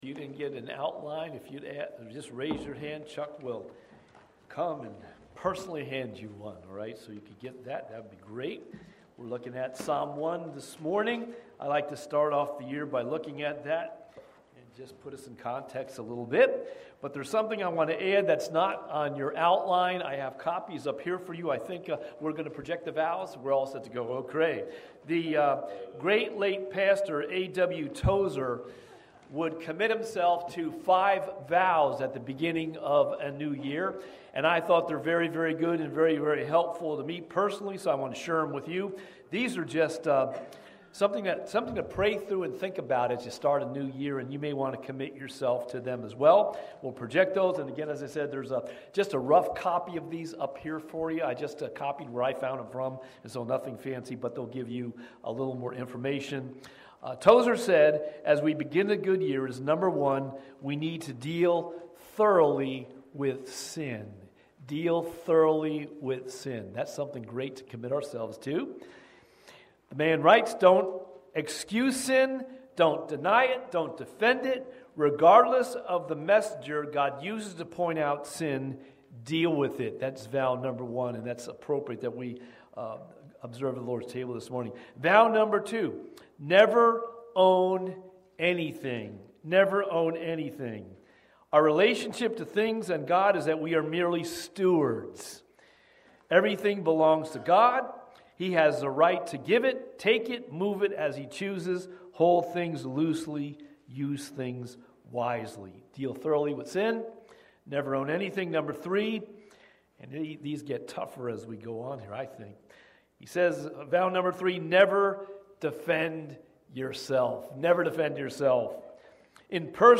Watch Online Service recorded at 9:45 Sunday morning.
Sermon Audio